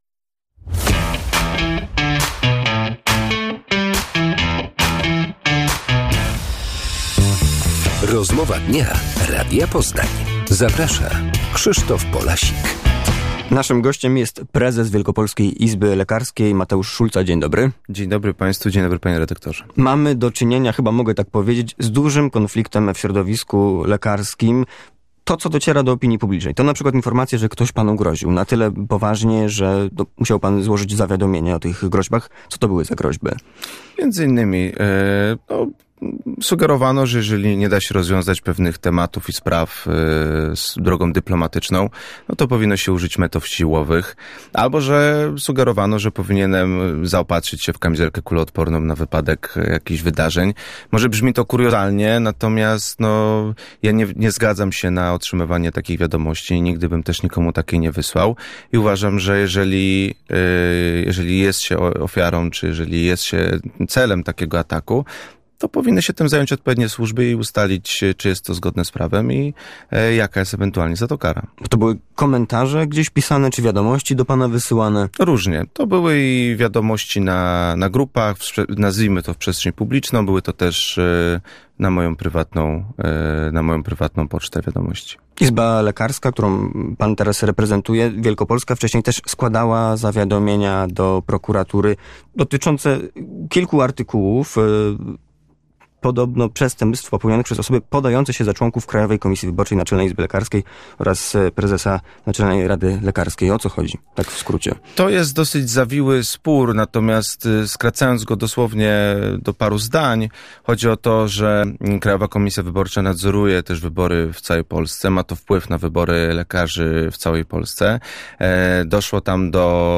pu8a8b79ezmilf2_wil_rozmowa.mp3